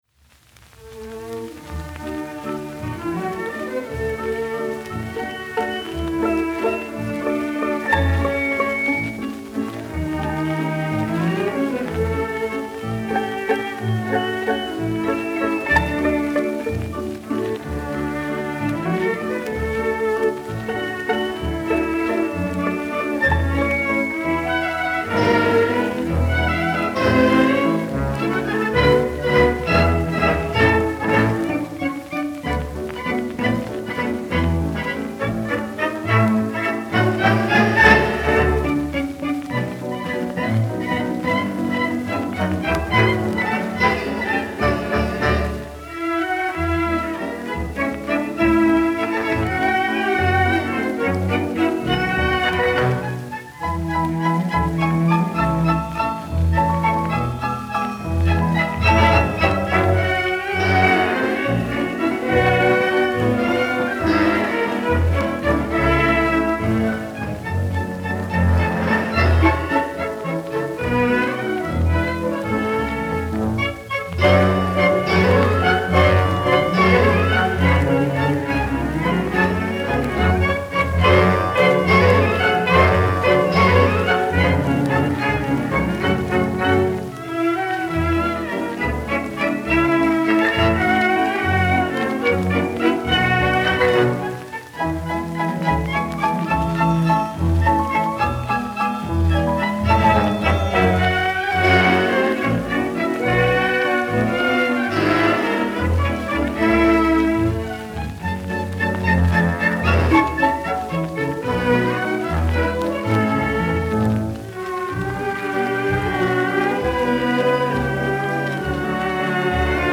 Instrumental music